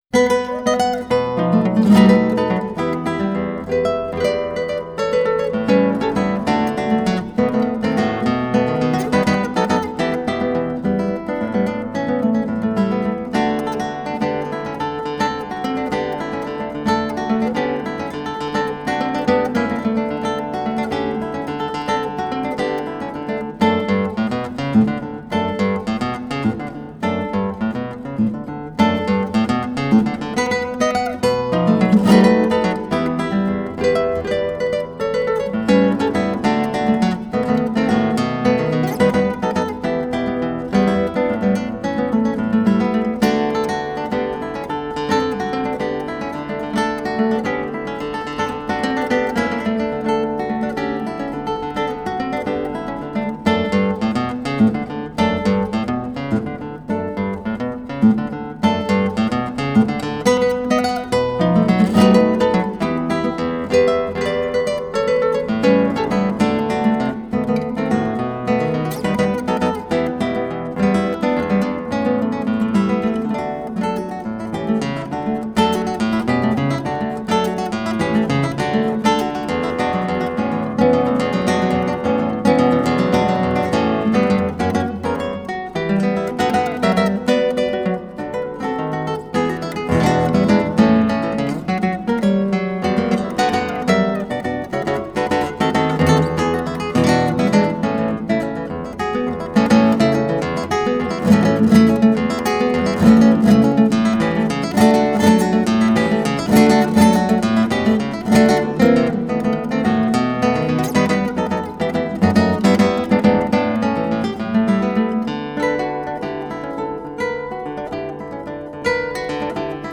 Música latina
Pars Today- La música de América Latina.